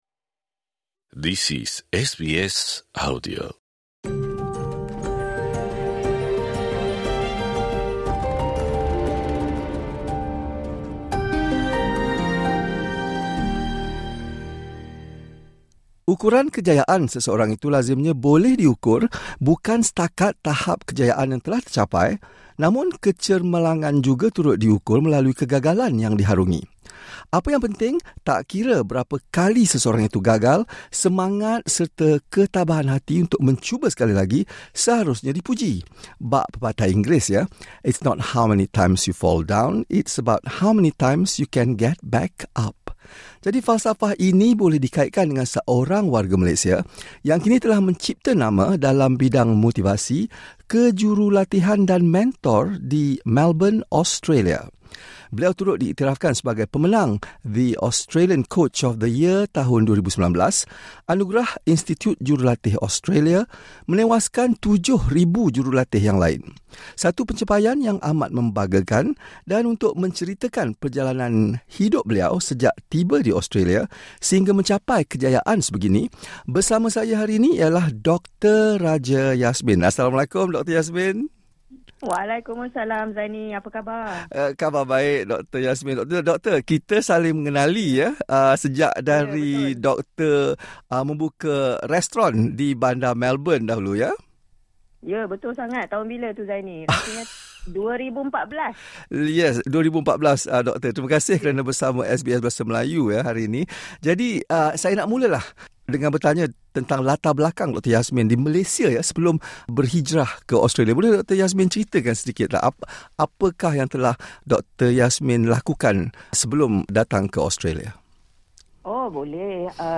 perbualan